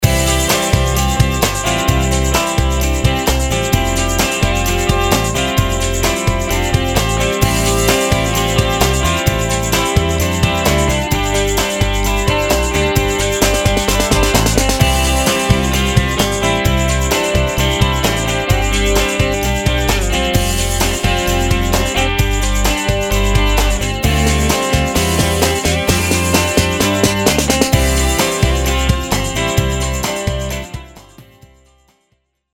Country Tracks, General Pop Tracks, Guitar pop tracks